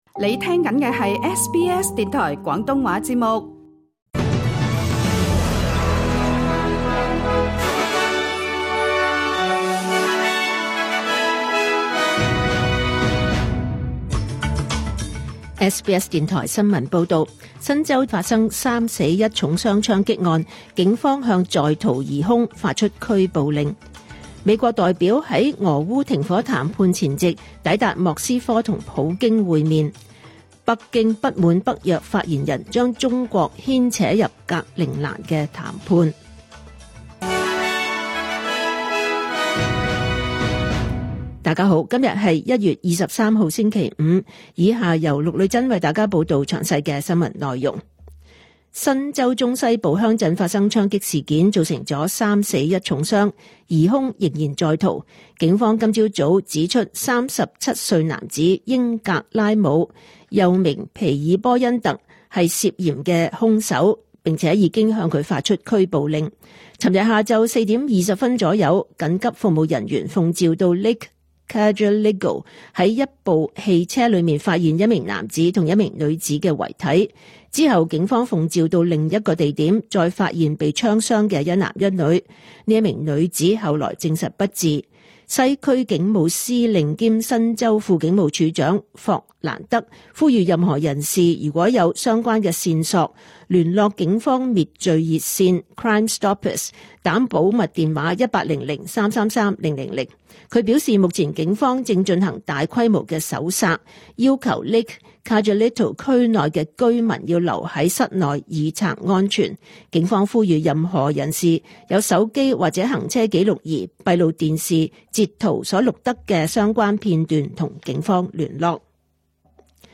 2026 年 1 月 23 日 SBS 廣東話節目詳盡早晨新聞報道。